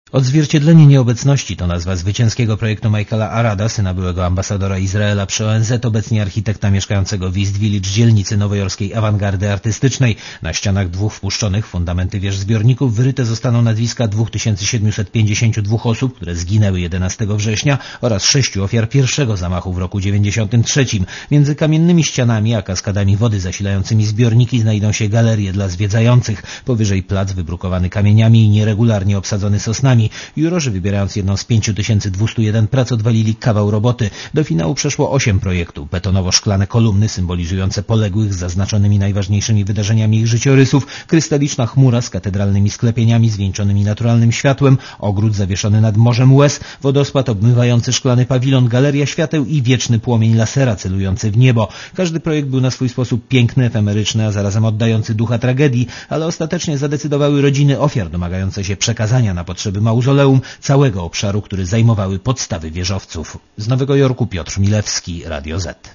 Korespondencja z USA (260Kb)